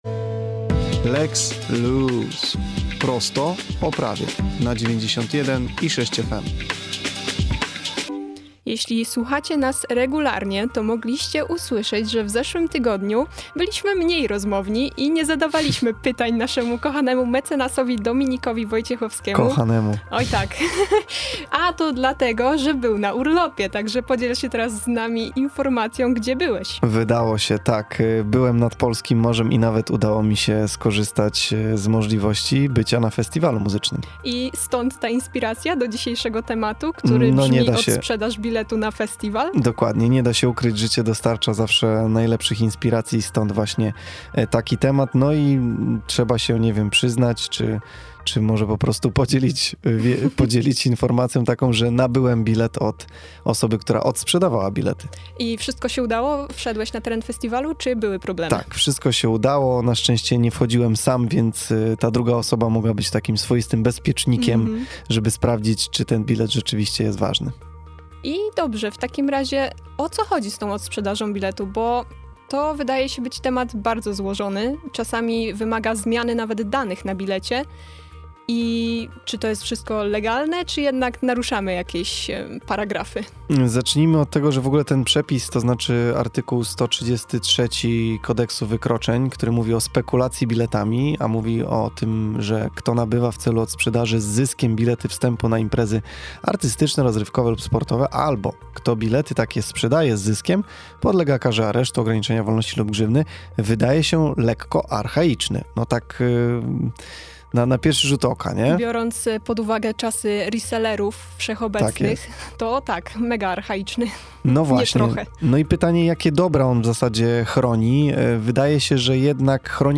Całość rozmowy, z audycji Dzieje się!, na temat spekulacji biletami znajdziecie poniżej.